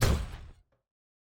Footstep Robot Large 2_10.wav